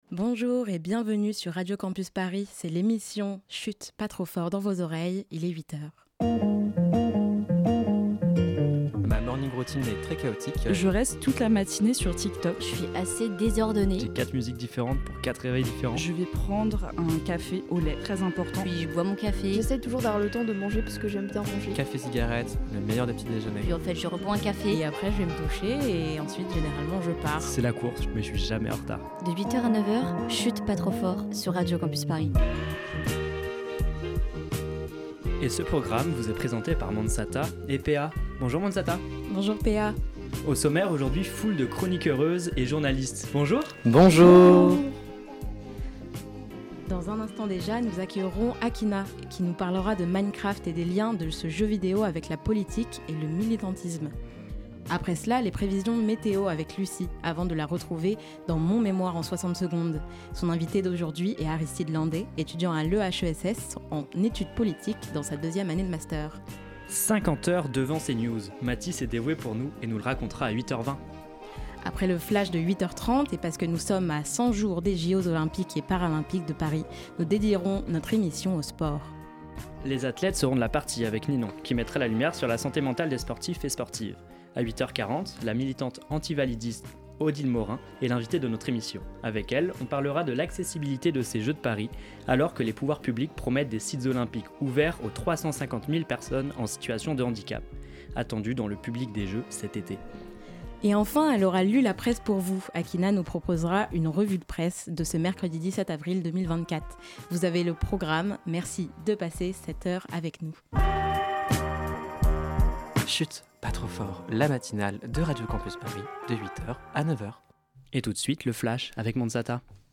Elle est l'invitée de notre émission réalisée par des étudiant.e.s qui ont suivi ce semestre un atelier d'initiation au journalisme radio.